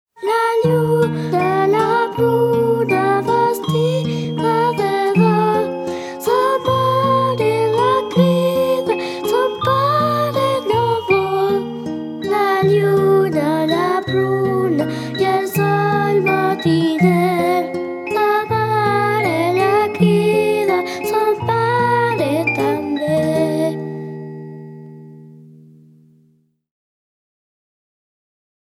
Canción de cuna en catalán La luna, la ciruela